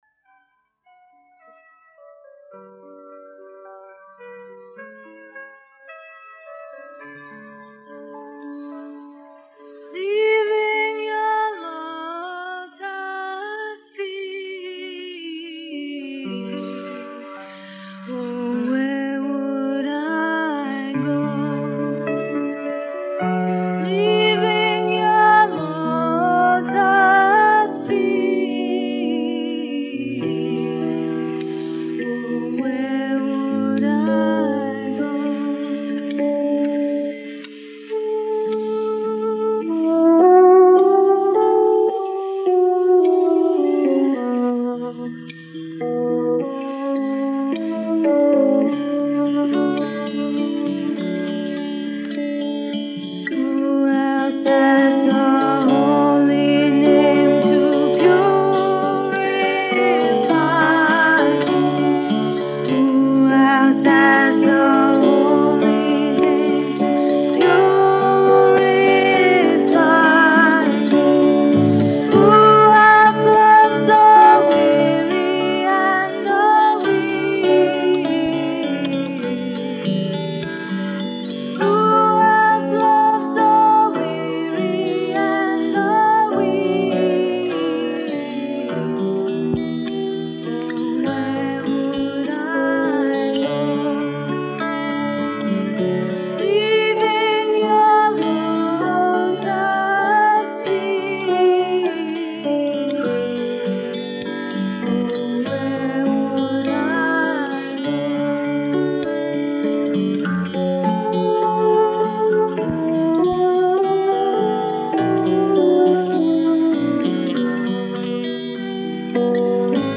1980 BHAJANS RECORDED AT THE HOLI 1980 FESTIVAL